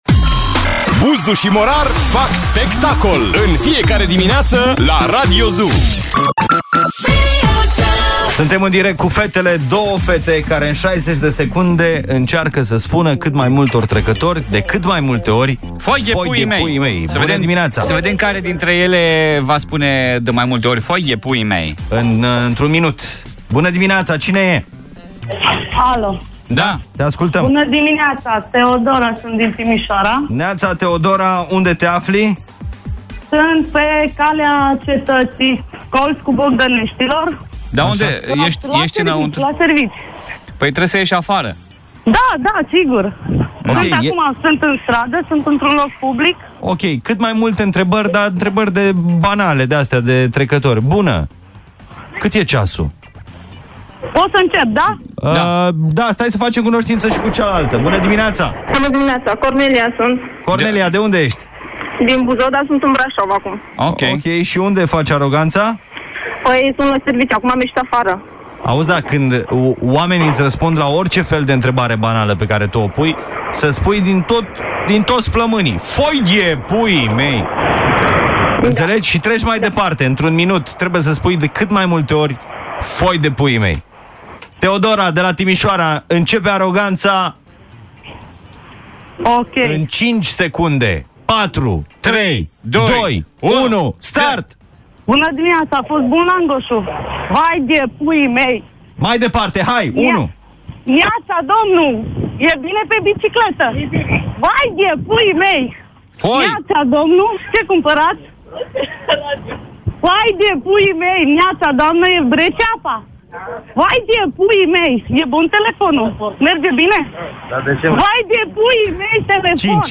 Azi, Buzdu si Morar au pus la cale o aroganta foarte vesela. Doua fete au avut cate 60 de secunde la dispozitie sa puna intrebari banale trecatorilor de pe strada si sa le raspunda cu un sincer "Pfoai de puii mei".